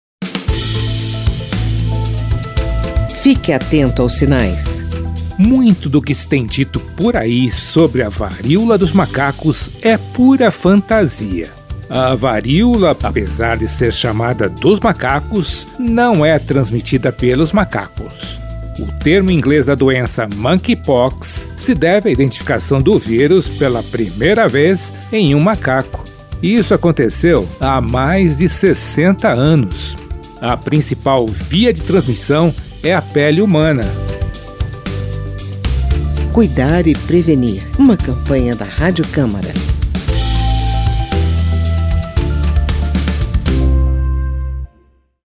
Texto e locução